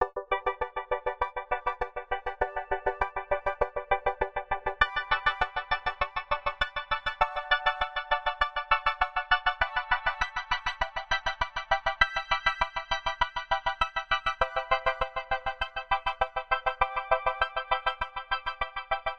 标签： 100 bpm Chill Out Loops Pad Loops 3.23 MB wav Key : E
声道立体声